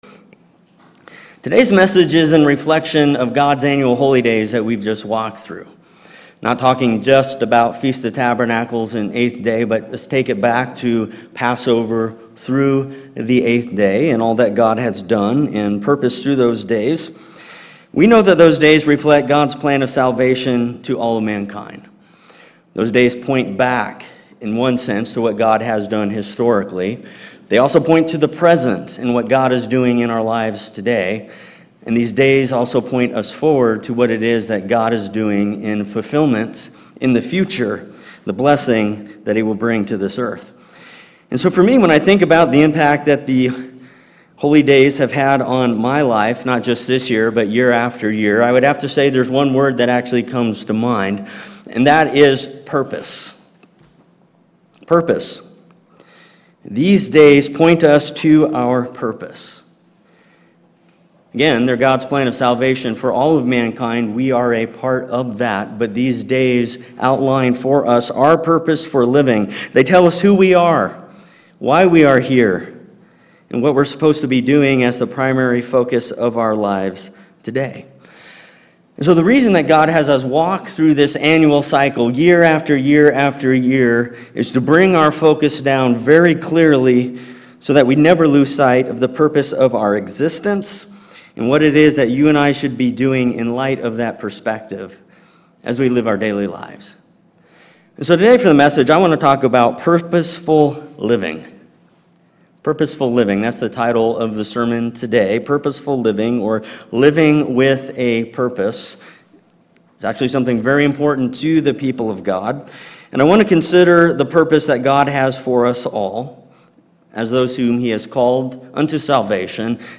This sermon defines some key elements that will help us stay focused. Everything we do should support our master plan.